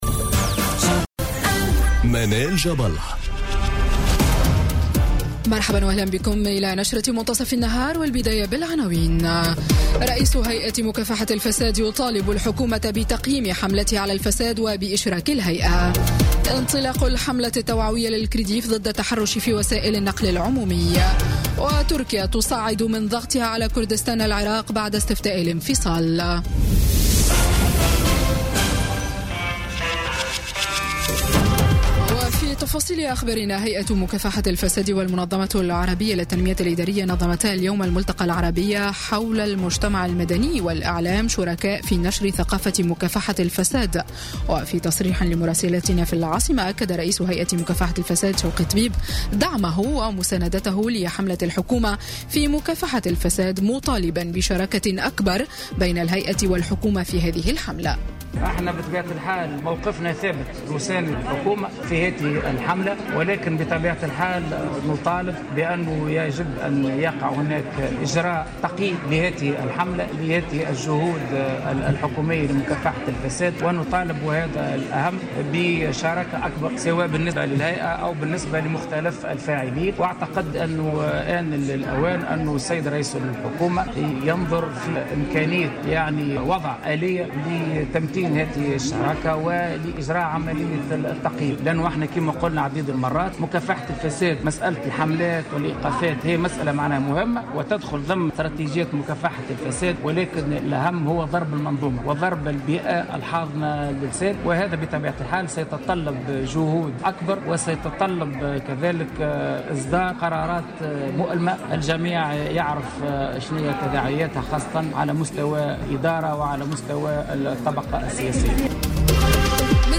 نشرة أخبار منتصف النهار ليوم الإثنين 25 سبتمبر 2017